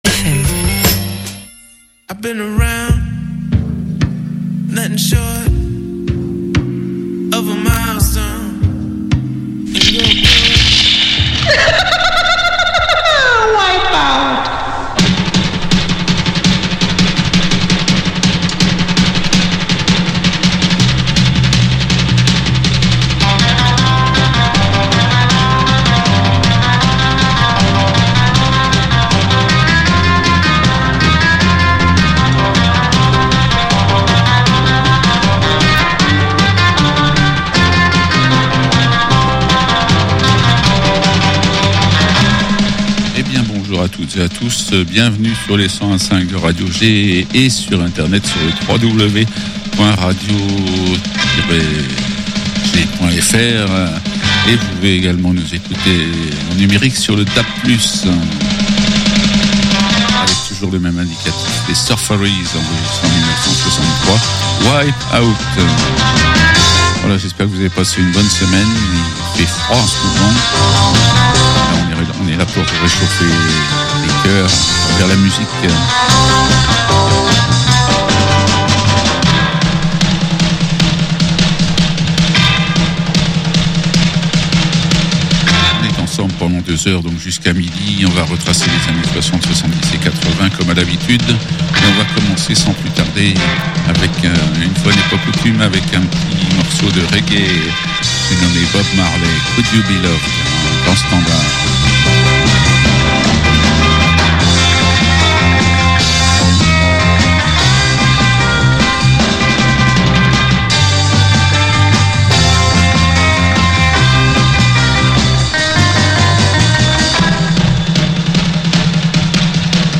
Magazine musical sur les années 70/80. Dédicaces locales.